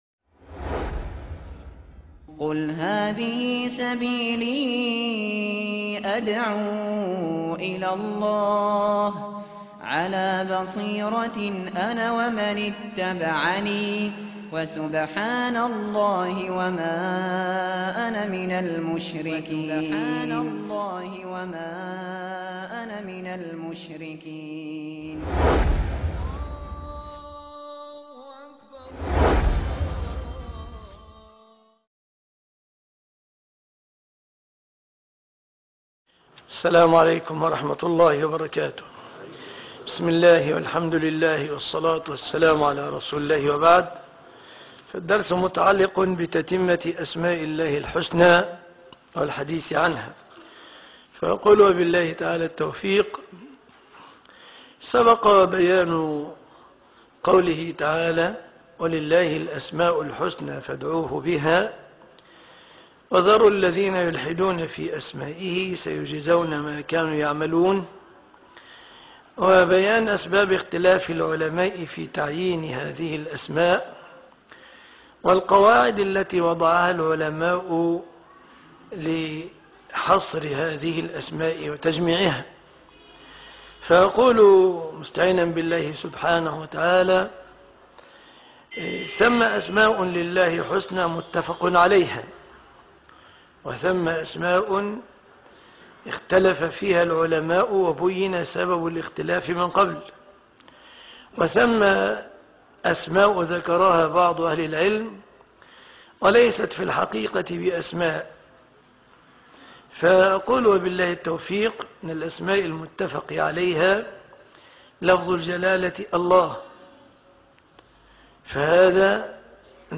تتمة الأسماء الحسنى (1/12/2015) دروس العقيدة - مجمع التوحيد بالمنصورة - فضيلة الشيخ مصطفى العدوي